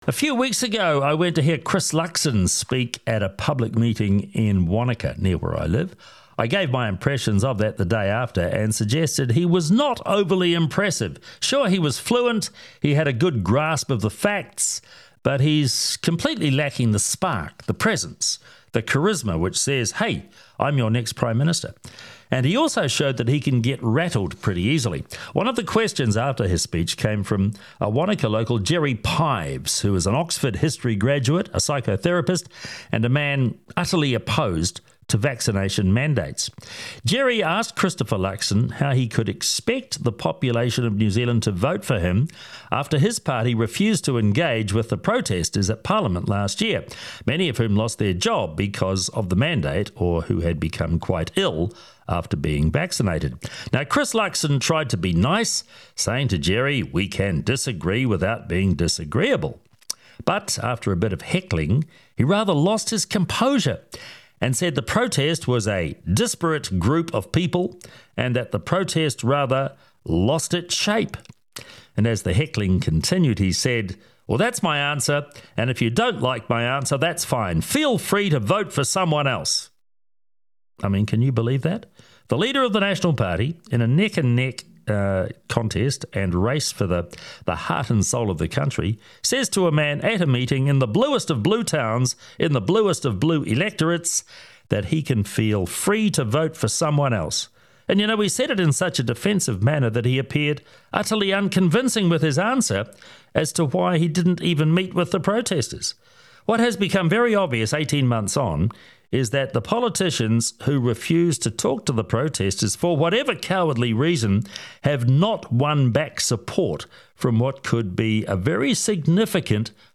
on a Nat party public meeting